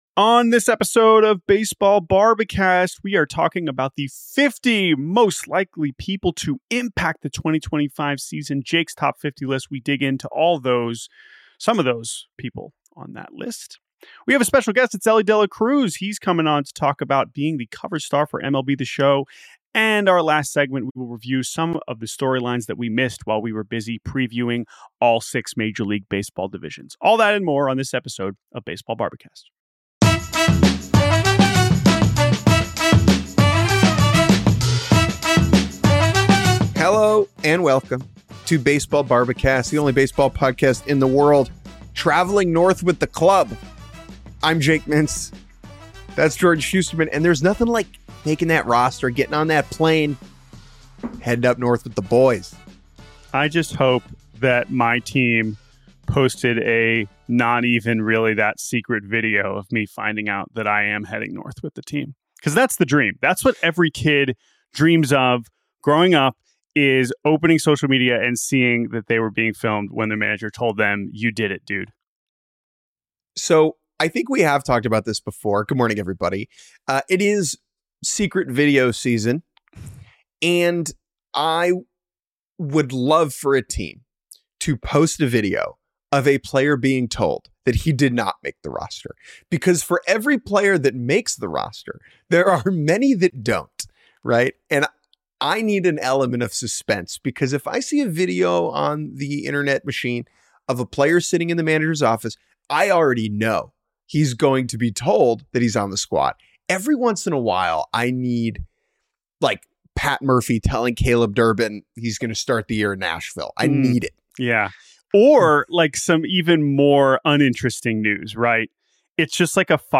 Top 50 most important MLB people in 2025 + Elly De La Cruz interview 1 hour 10 minutes Posted Mar 24, 2025 at 7:37 pm .